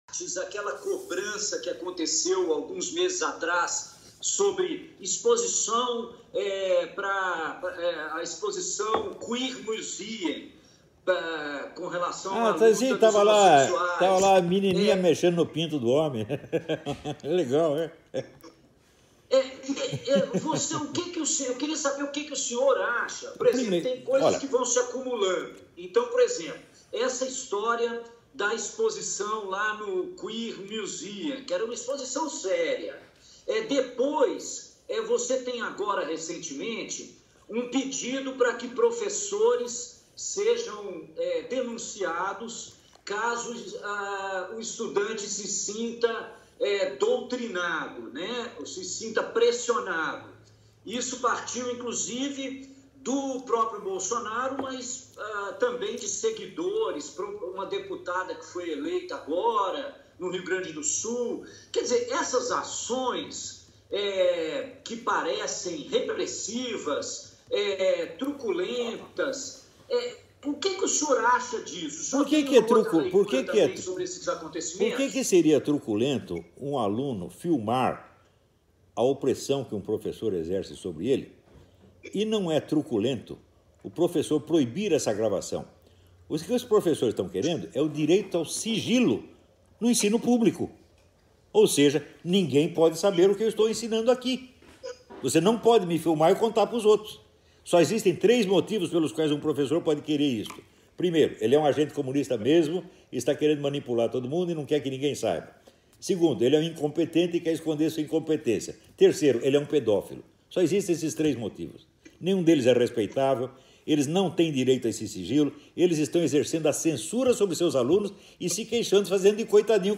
Entrevista à Carta Capetal (11 de nov. de 2018 - Olavo de Carvalho)